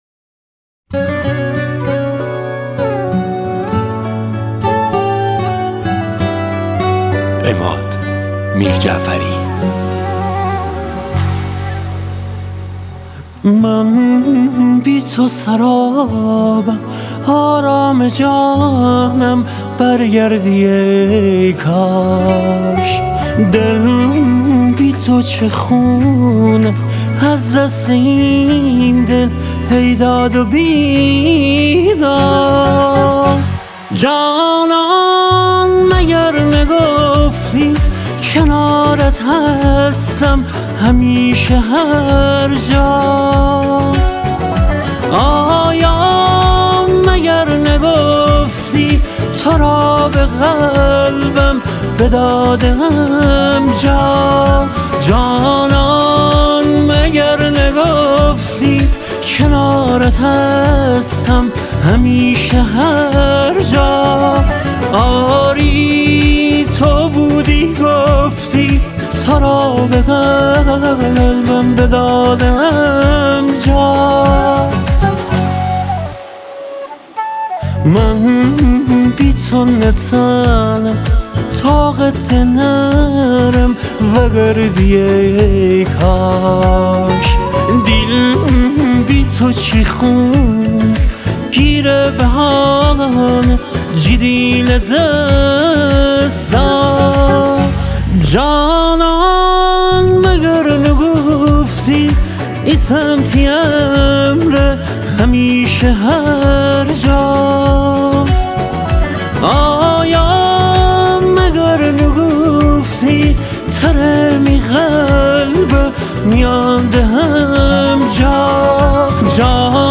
غمگین
آهنگ غمگین مازندرانی